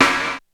Rimshot.wav